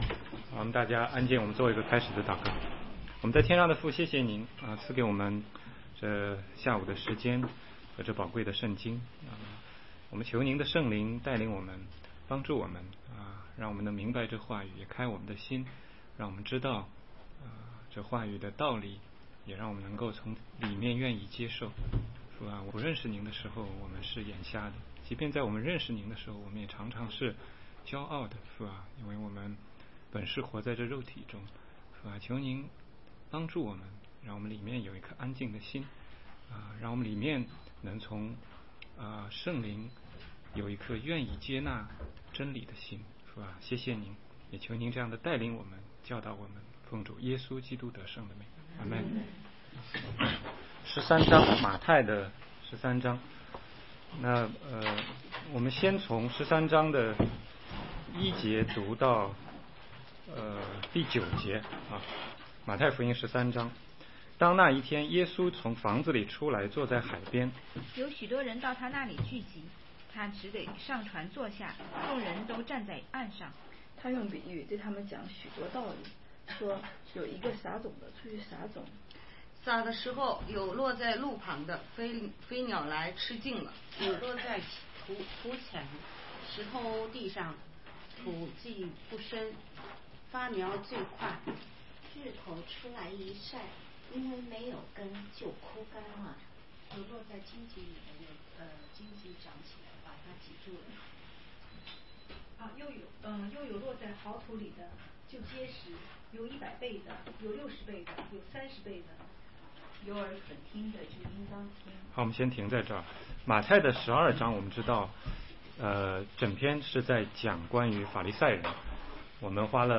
16街讲道录音 - 马太福音13章1-23节